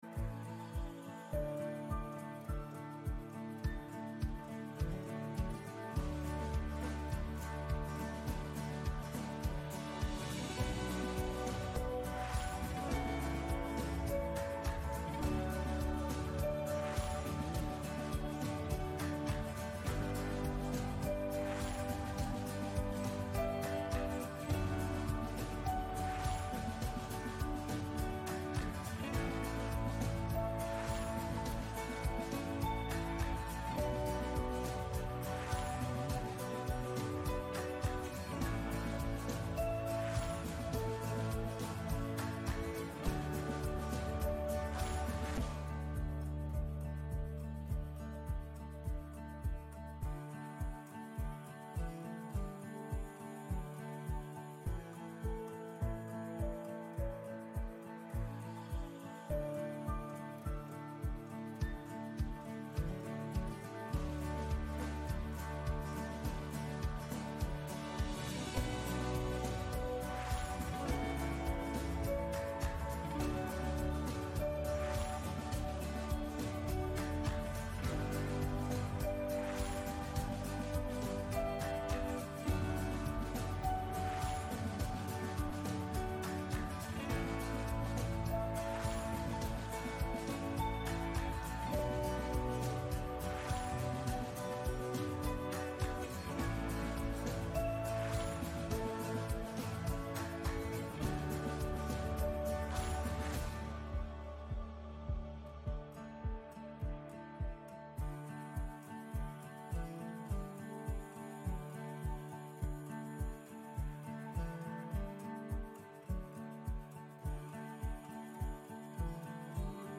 Gottesdienst am 25. August 2024 aus der Christuskirche Altona